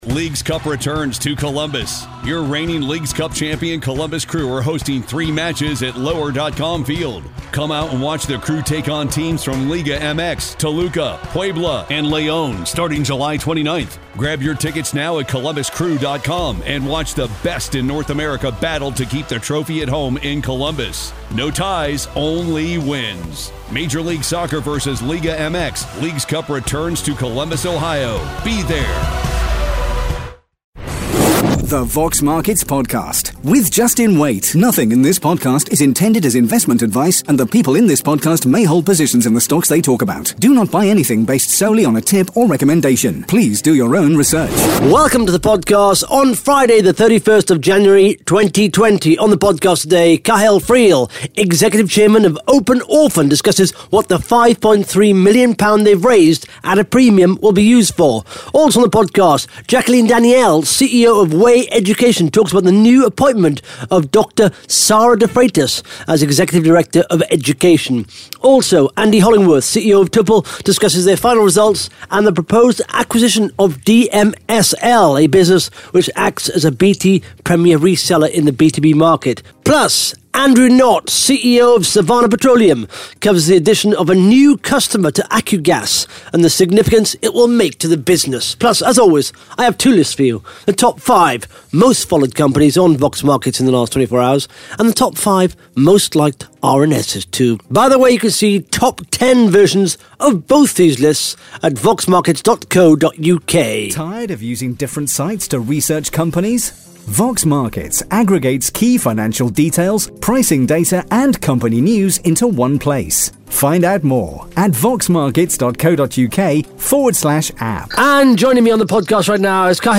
(Interview starts at 20 minutes 50 seconds)